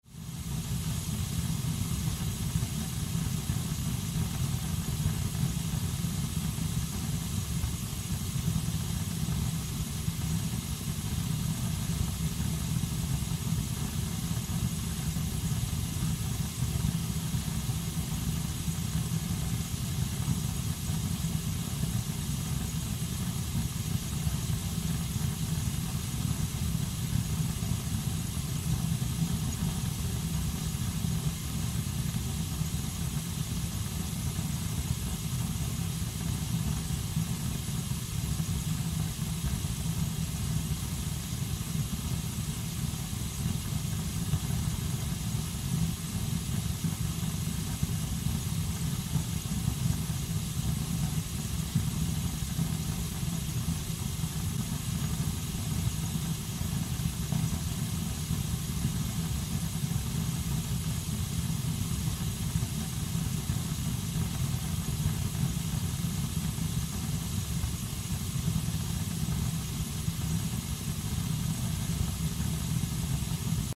Звуки утечки газа
Аудиофайлы передают характерное шипение, свист и другие звуки, сопровождающие утечку бытового или природного газа.